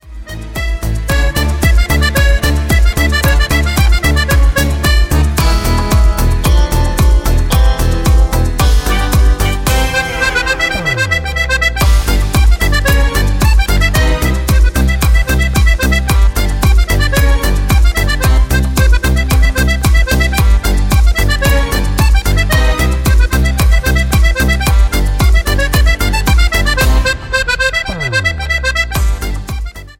CUMBIA  (03.18)